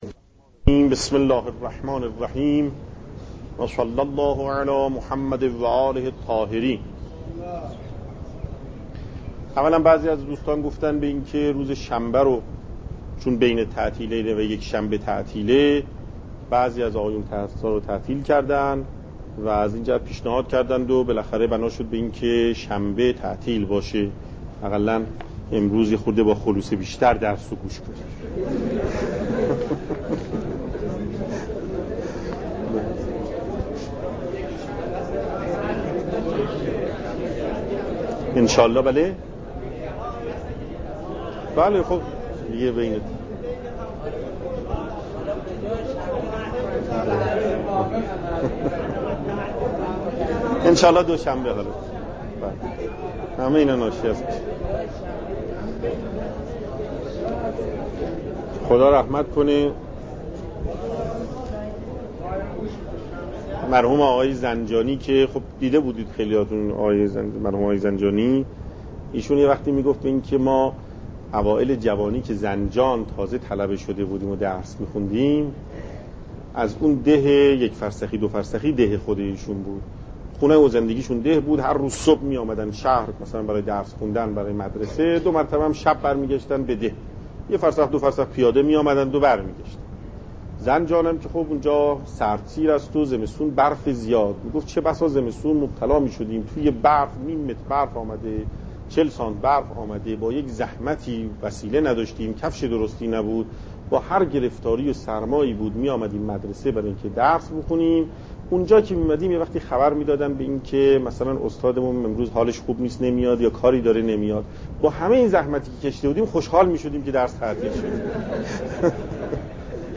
درس مکاسب